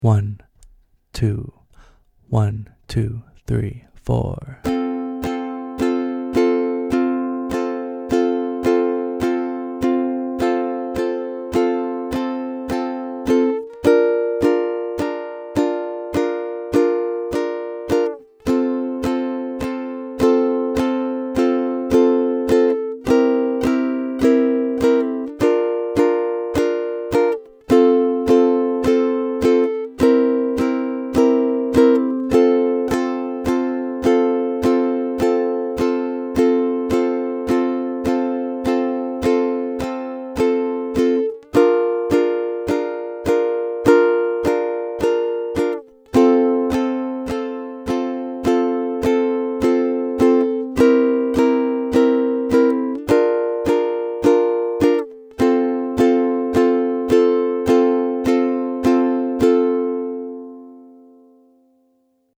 Backing Track: 12 Bar Blues - Key of C